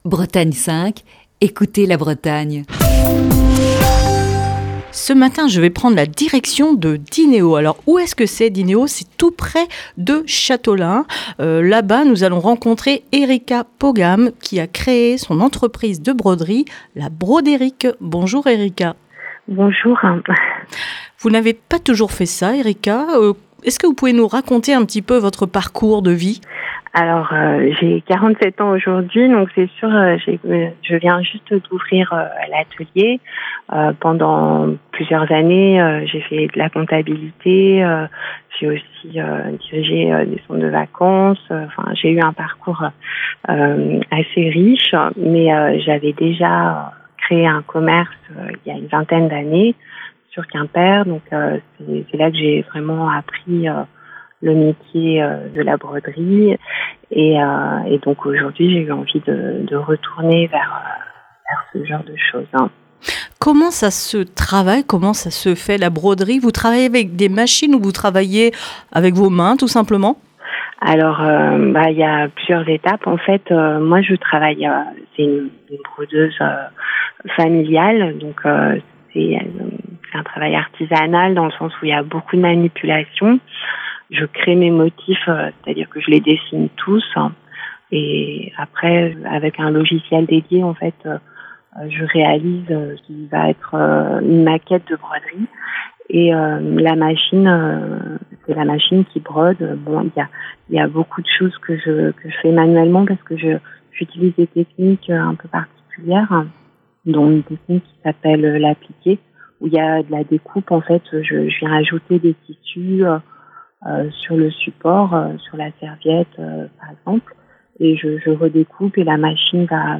Ce jeudi dans le coup de fil du matin